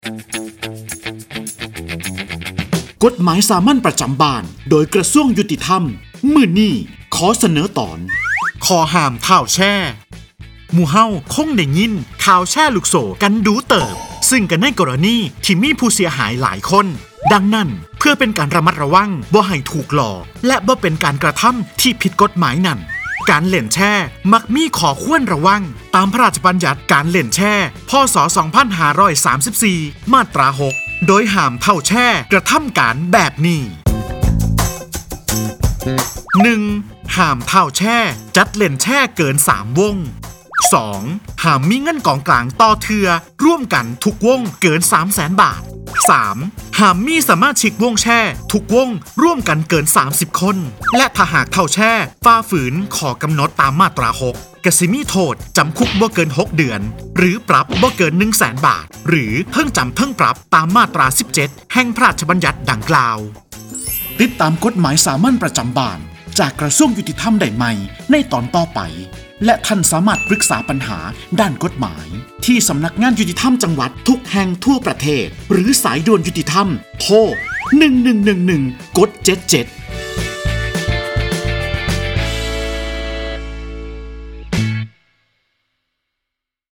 กฎหมายสามัญประจำบ้าน ฉบับภาษาท้องถิ่น ภาคอีสาน ตอนข้อห้ามท้าวแชร์
ลักษณะของสื่อ :   บรรยาย, คลิปเสียง